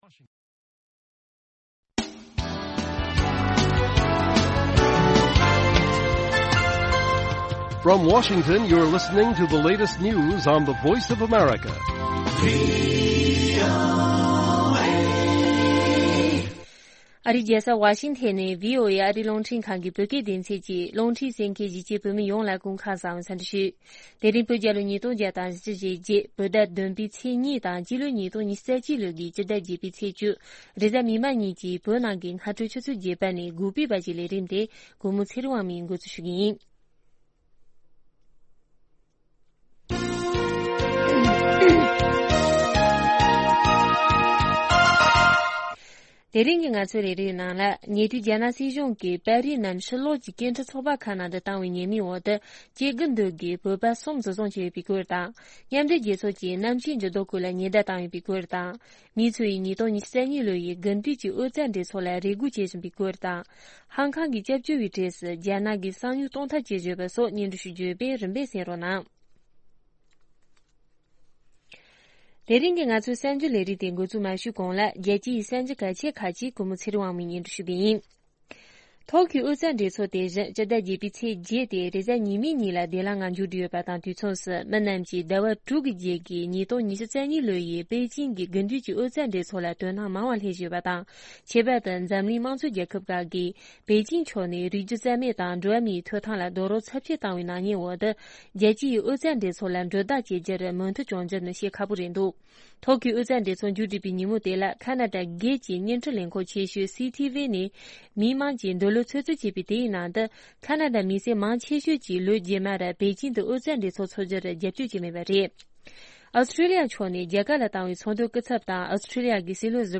སྔ་དྲོའི་རླུང་འཕྲིན།